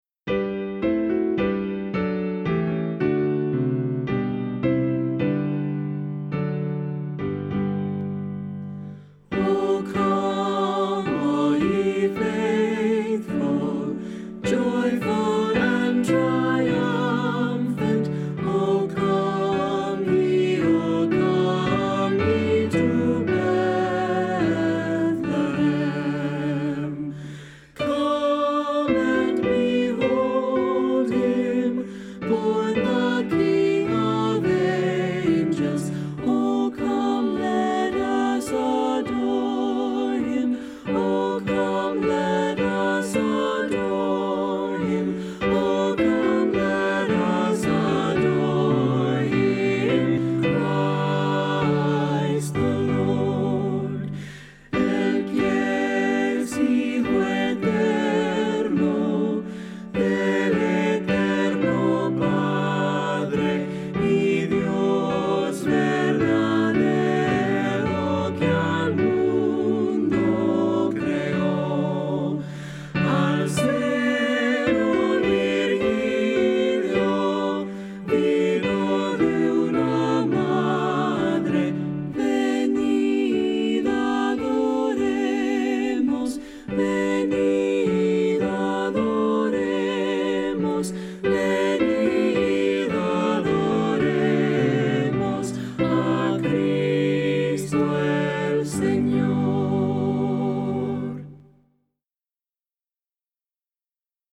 Voicing: SAB; Assembly